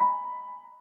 piano47.ogg